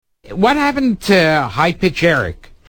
High pitch
Category: Comedians   Right: Personal
Tags: Comedian Gilbert Gottfried Actor Stand-up comedian Gilbert Gottfried audio clips